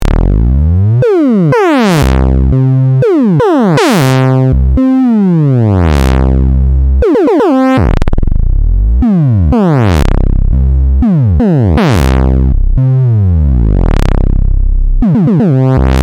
20-SLIDE-TECHNO-02.mp3